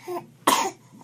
cough.ogg